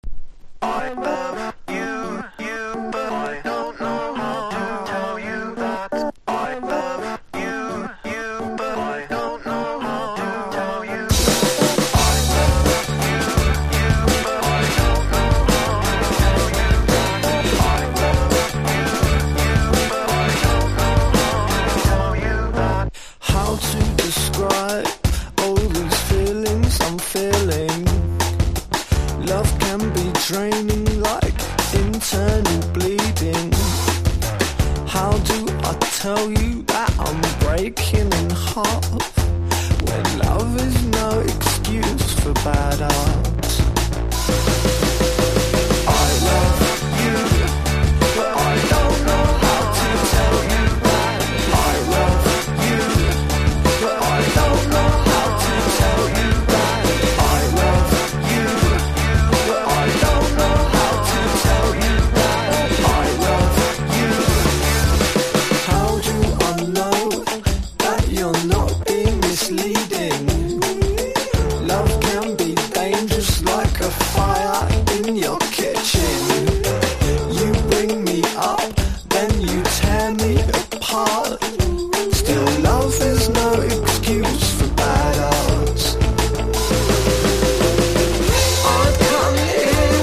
1. 00S ROCK >
INDIE DANCE